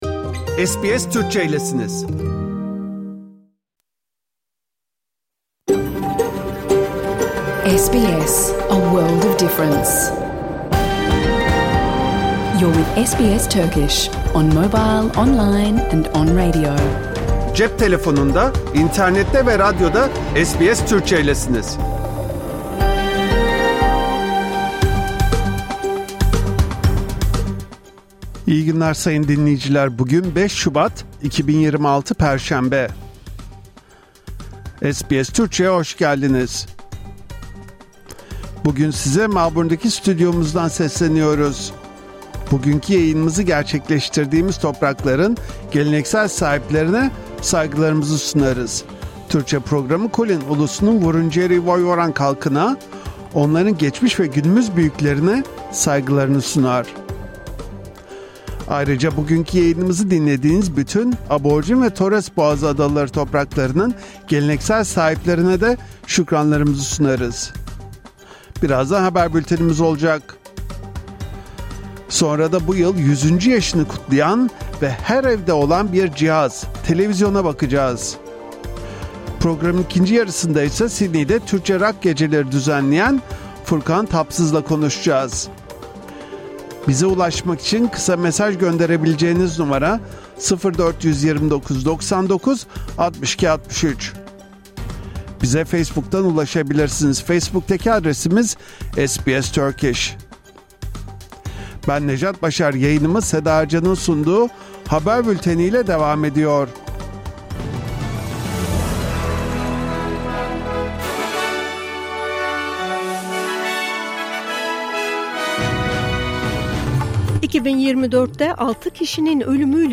Hafta içi Salı hariç her gün Avustralya doğu kıyıları saati ile 14:00 ile 15:00 arasında yayınlanan SBS Türkçe radyo programını artık reklamsız, müziksiz ve kesintisiz bir şekilde dinleyebilirsiniz. 🎧
🎧 PROGRAM İÇERİĞİ HABER BÜLTENİ. Yüz yıl önce İskoç mucit John Logie Baird, Televisor adını verdiği ilk televizyonu dünyaya tanıttı.